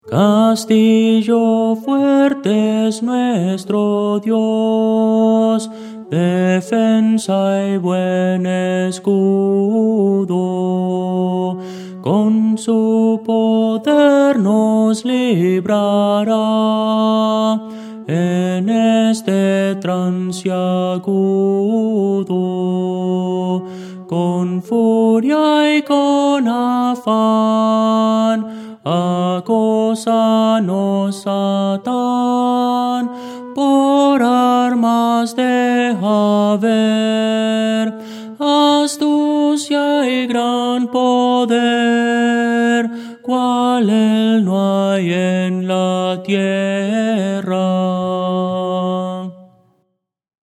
Voces para coro
Tenor – Descargar
Audio: MIDI